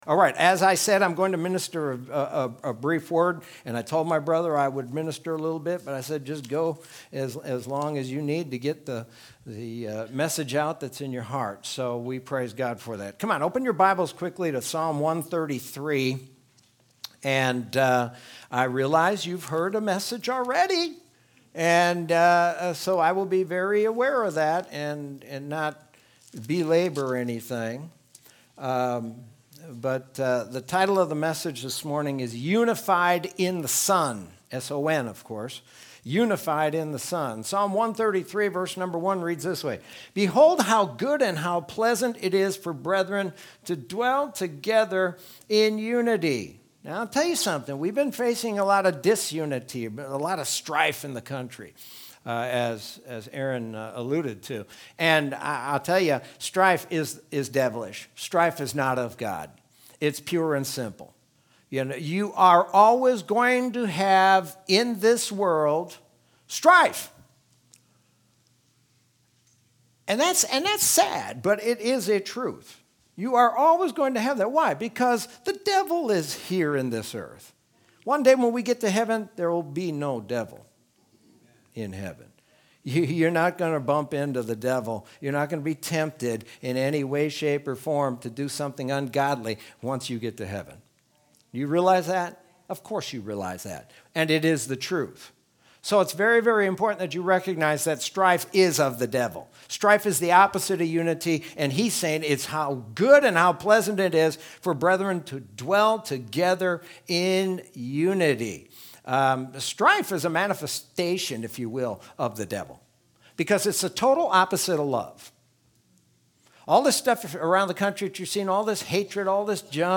Sermon from Sunday, October 11th, 2020.